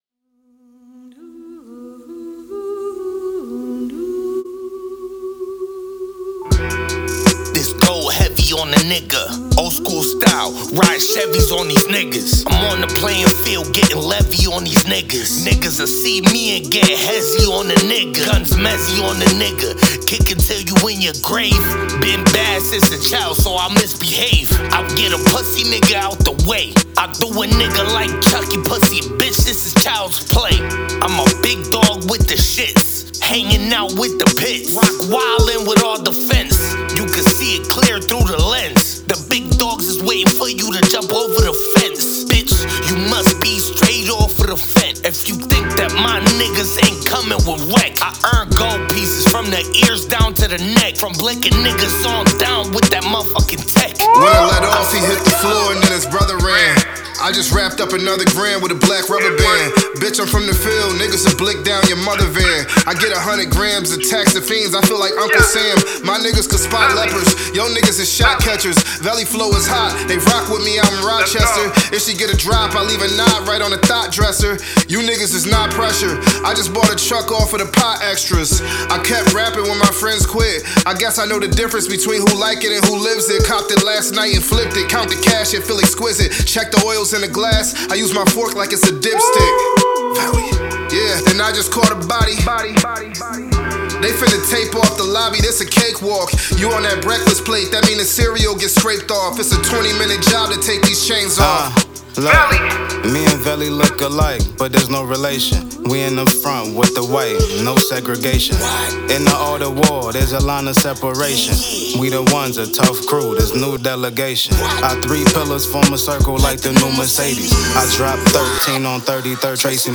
Your Source For Hip Hop News